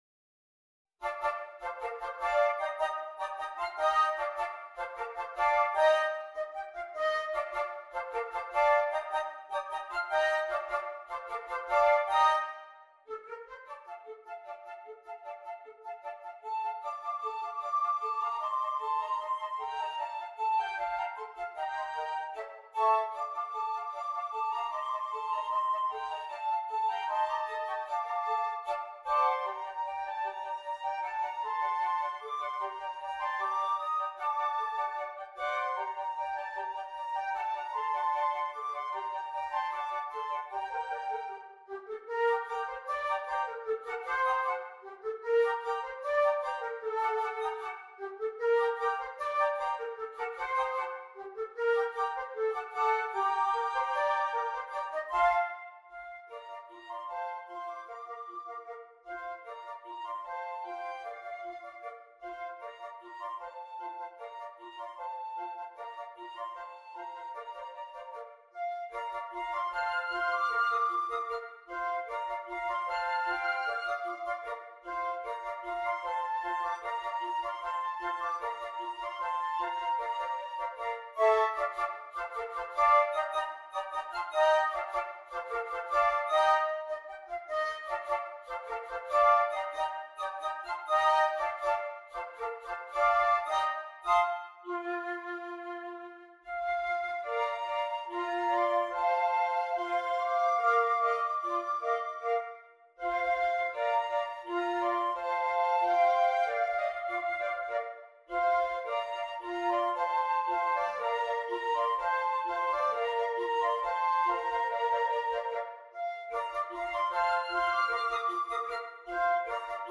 5 Flutes